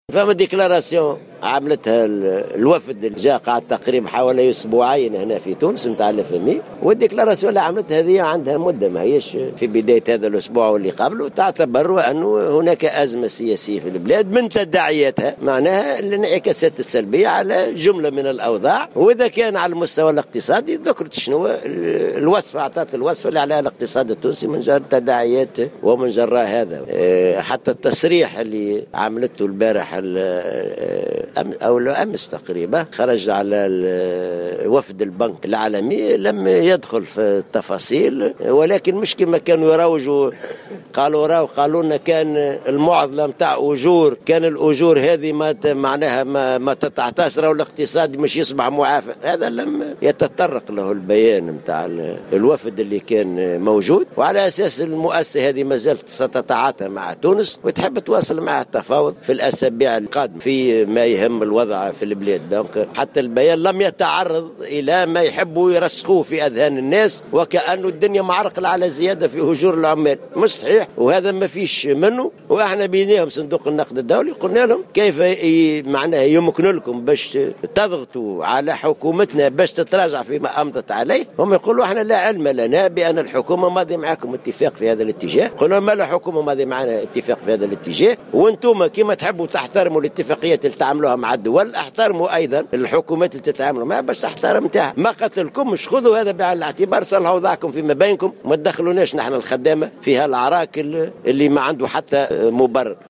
قال الأمين العام للاتحاد العام التونسي للشغل حسين العباسي على هامش ندوة تكوينية قطاعية نظمتها اليوم السبت 12 نوفمبر 2016 الجامعة العامة للكيمياء والنفط حول دور الجباية في دفع التنمية بالحمامات إنه لا علاقة لتصريح المديرة العامة لصندوق النقد الدولي كريستين لاغارد والوفد المرافق لها الذي زار تونس مؤخرا بتراجع الحكومة عن اتفاق الزيادة في الأجور.